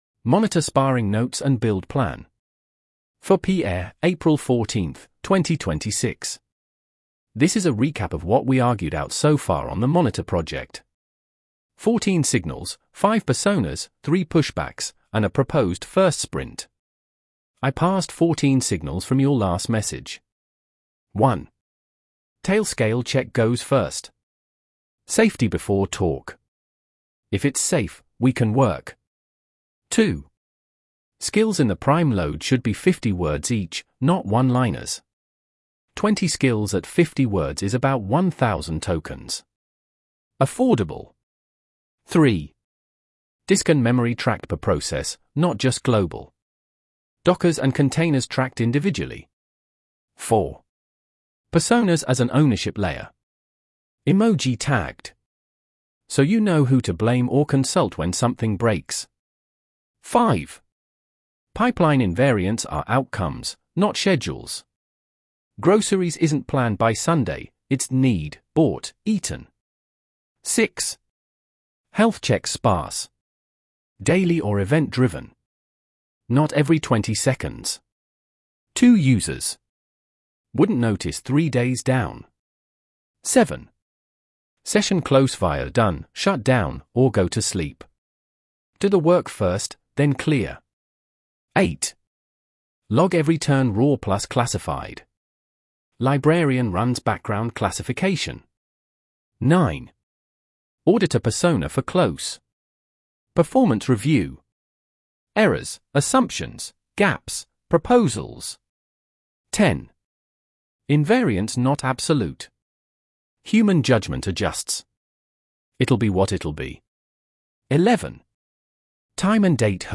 ~7 minute read · Edge TTS Ryan (en-GB)